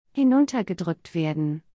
/hɪnʊntɐˈdʁʏkən/ · /ˈdʁʏkt hɪnʊntɐ/ · /ˈdʁʏk.tə hɪnʊntɐ/ · /hɪnʊntɐɡəˈdʁʏkt/